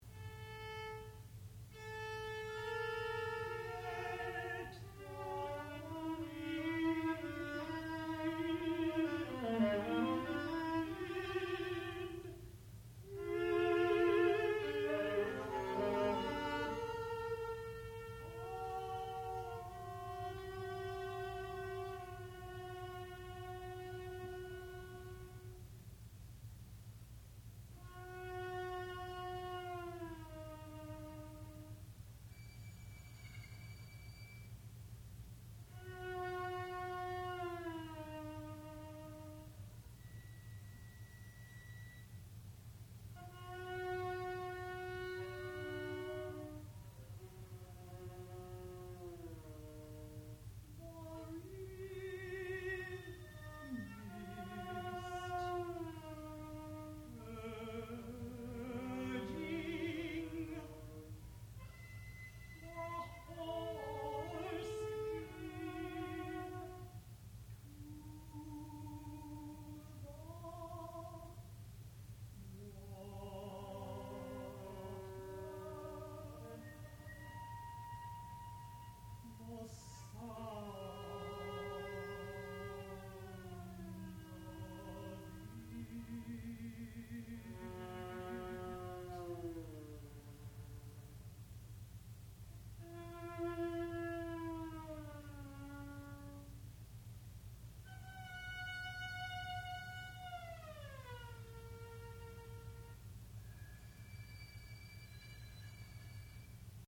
sound recording-musical
classical music
mezzo-soprano
viola